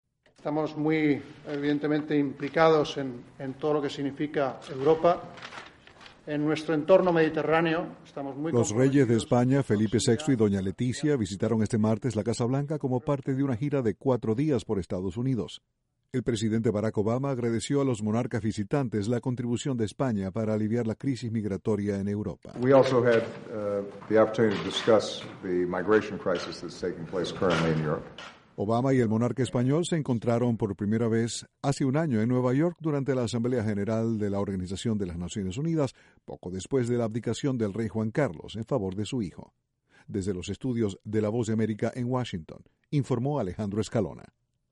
: Los reyes de España visitaron La Casa Blanca, este martes. Desde la Voz de América, Washington